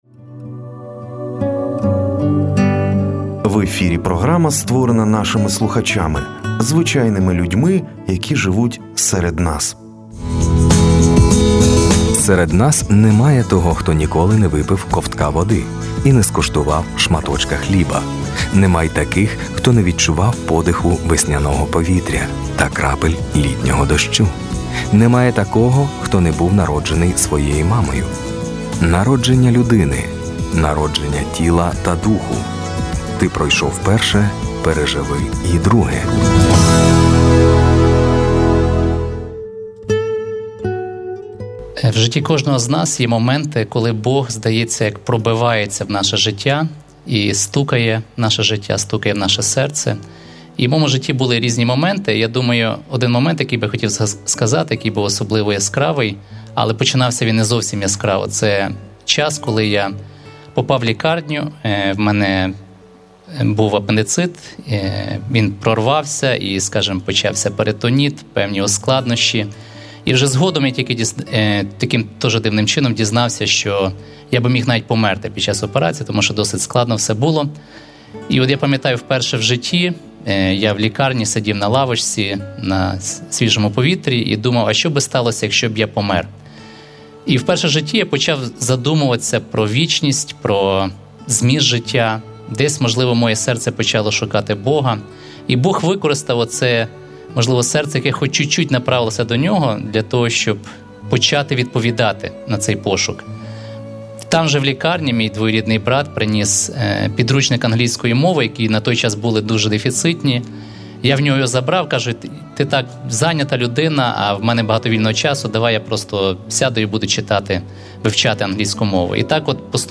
Чоловік ділиться особистим свідченням, як небезпечна ситуація стала переломним моментом. Історія про Боже втручання там, де здавалося, вже не було надії.